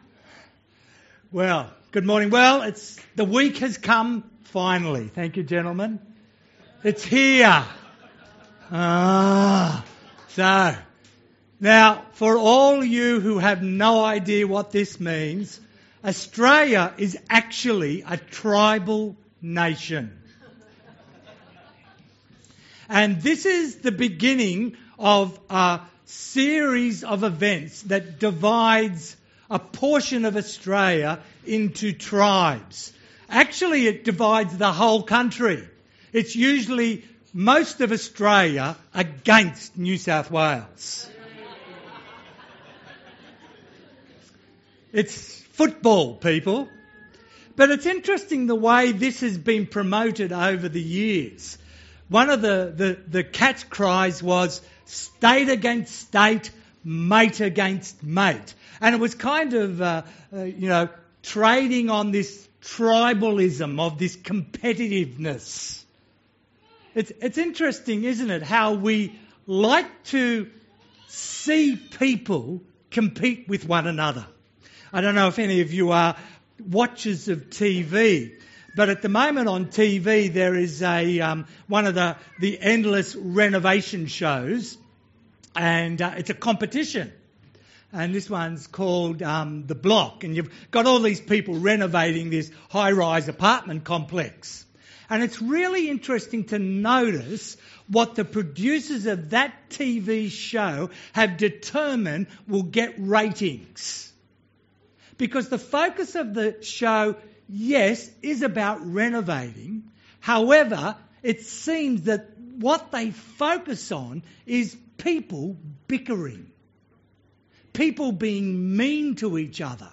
Ephesians 2:11-18 Listen to the sermon. Categories Sermon Tags ephesians